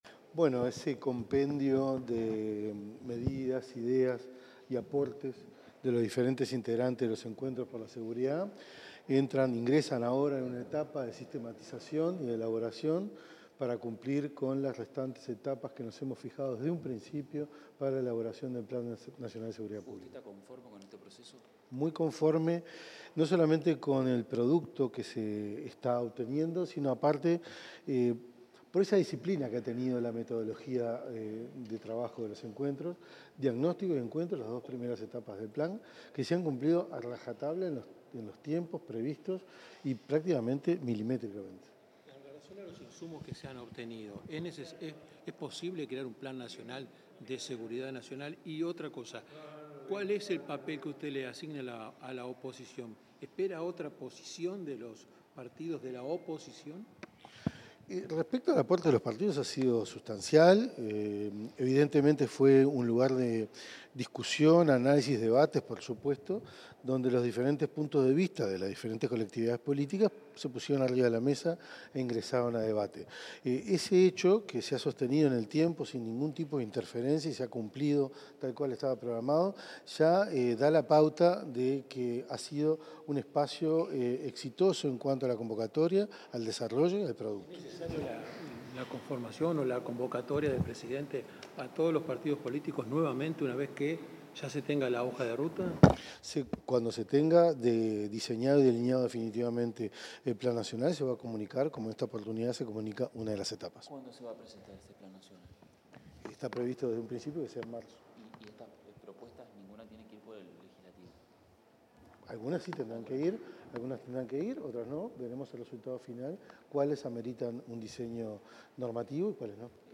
Declaraciones del ministro del Interior, Carlos Negro
En el marco del cierre de los Encuentros por Seguridad, el ministro del Interior, Carlos Negro, realizó declaraciones.